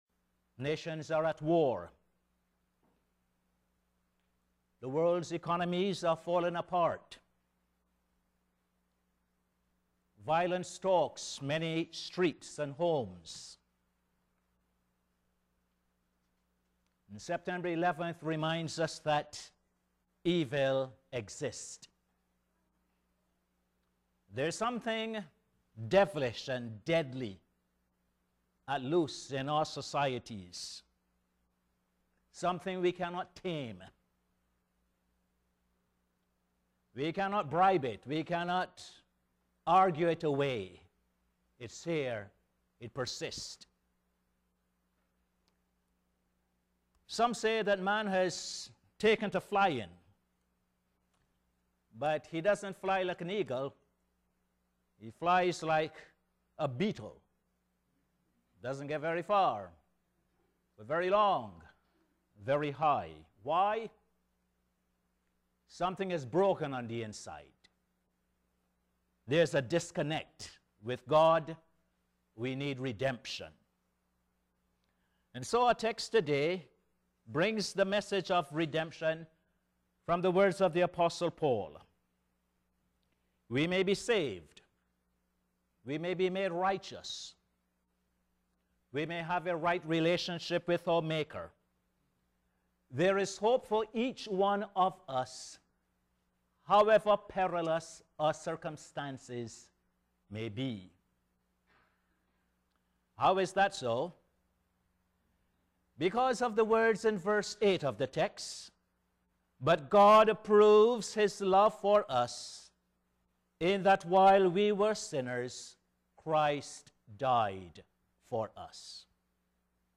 Posted in Sermons on 06.